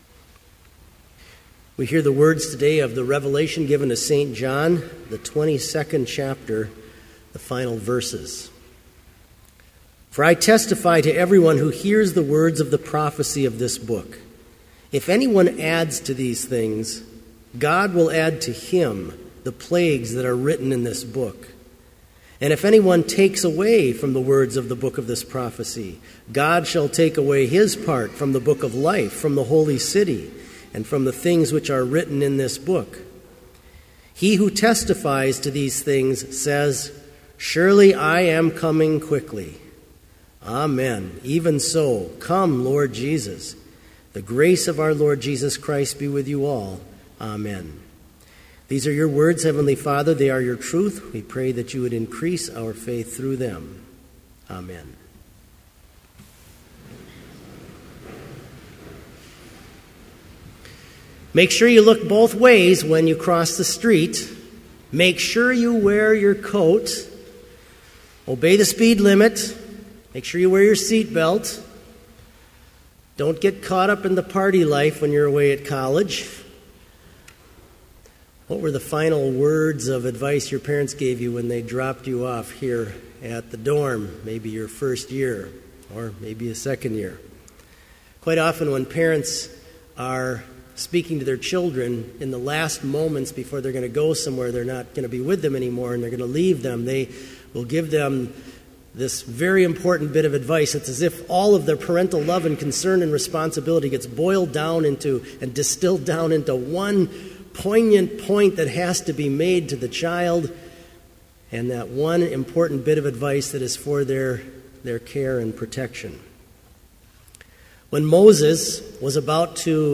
Complete service audio for Chapel - February 26, 2014